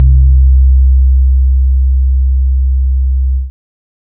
Bass (1).wav